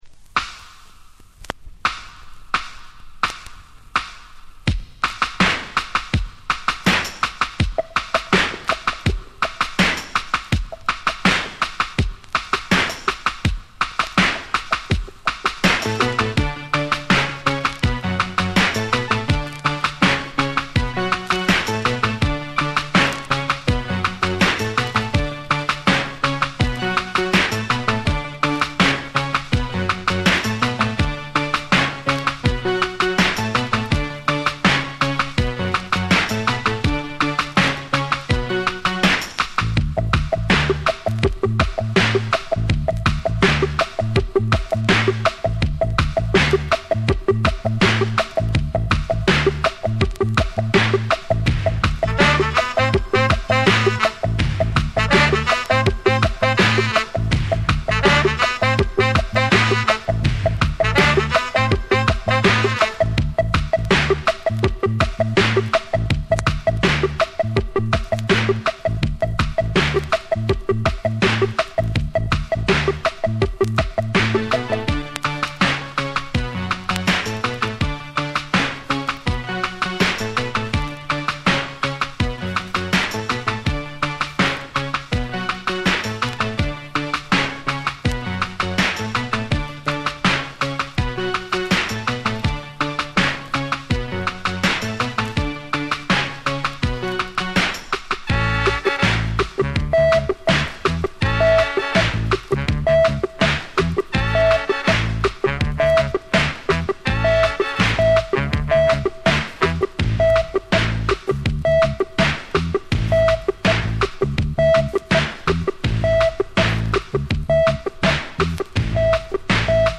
Summer Of Dub Mix
Hybrid Salsa Remix